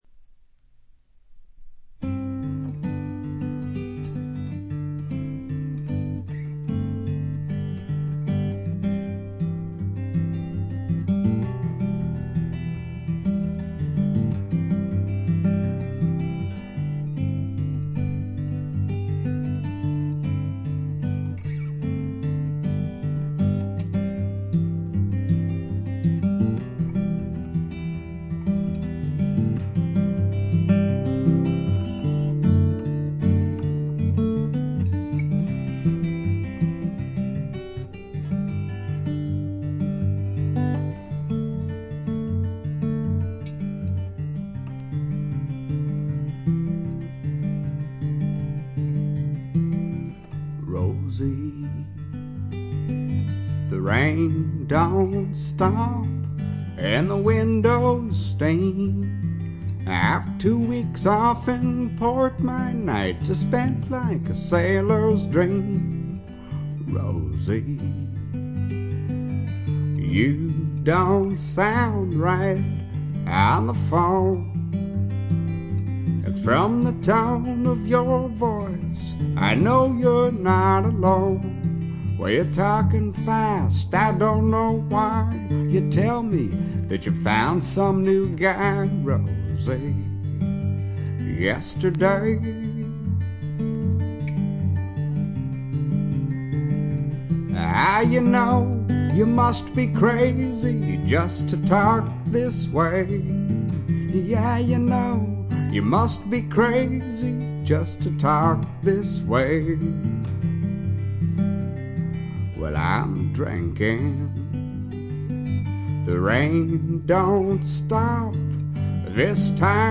ごくわずかなノイズ感のみ。
試聴曲は現品からの取り込み音源です。